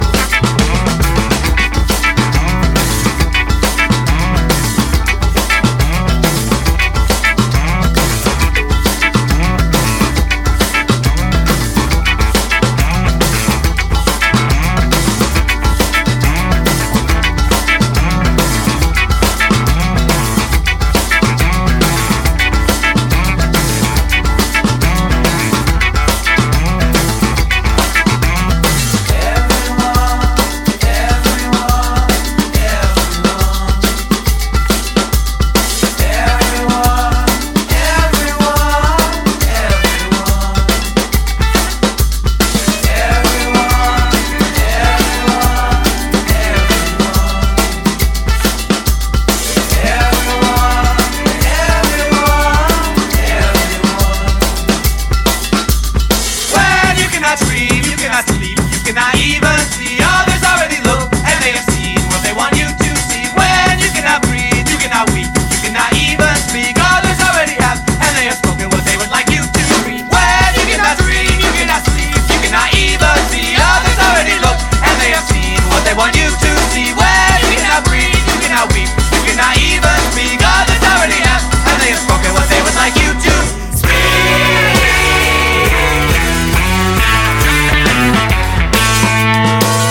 WORLD / LATIN / LATIN JAZZ
FOR DJな使えるラテン・ジャズ/ラテン・グルーヴ！